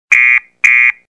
alarm2.mp3